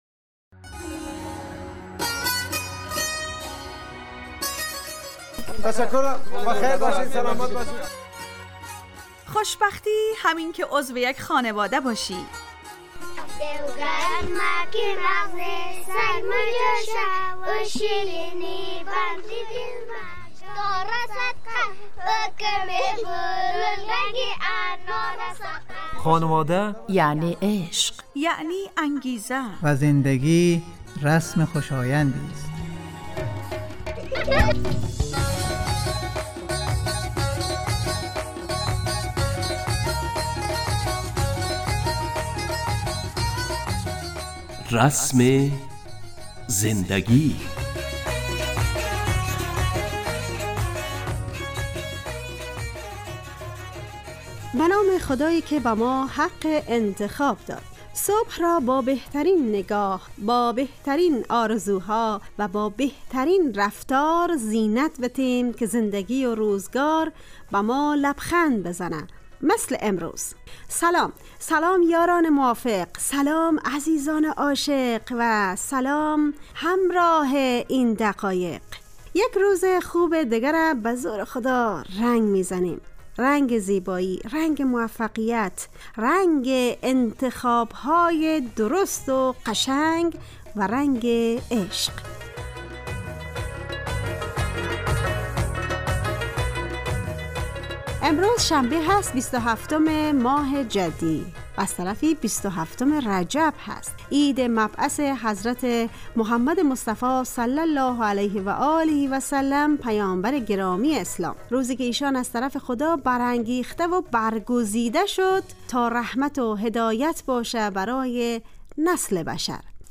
رسم زندگی_ برنامه خانواده رادیو دری